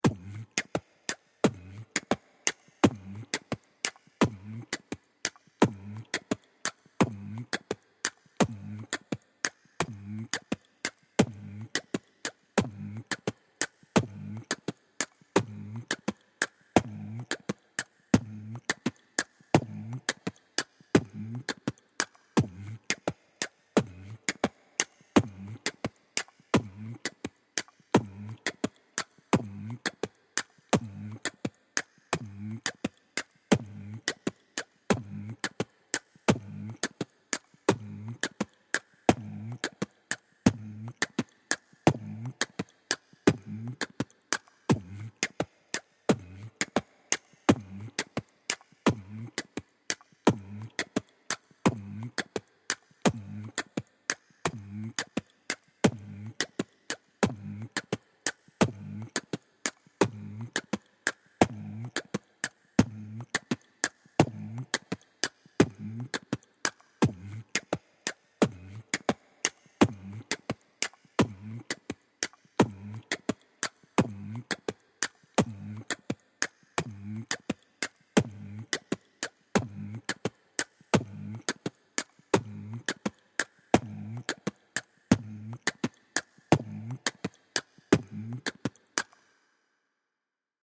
Il est egalement possible d’ajouter un ostinato rythmique sous forme d’un Beat Box.
Le Beat Box :
c3_-_malaika_-_beat_box.mp3